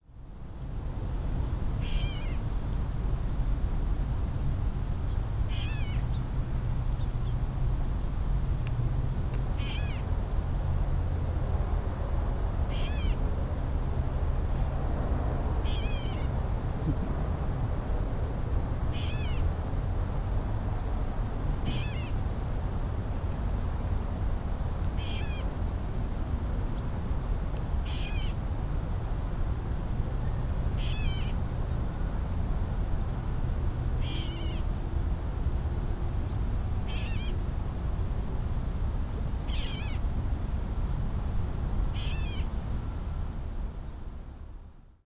Gray Catbird
Dumetella carolinensis